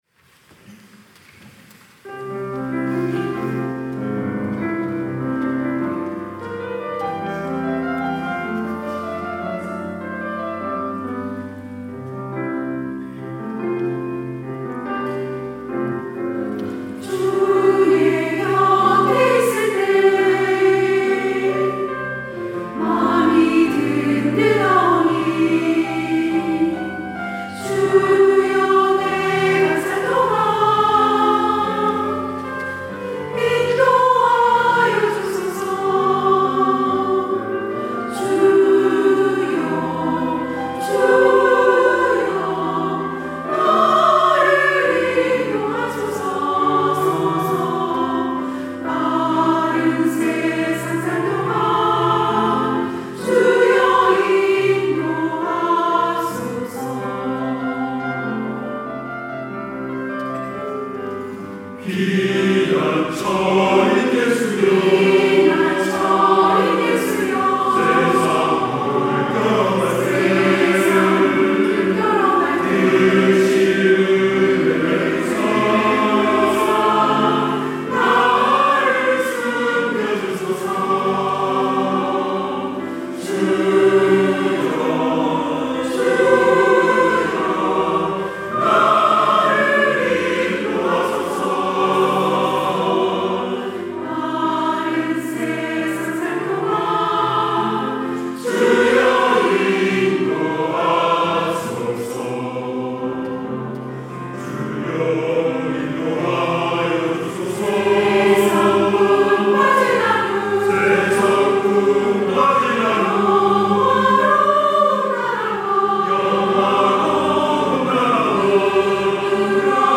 시온(주일1부) - 주의 곁에 있을 때
찬양대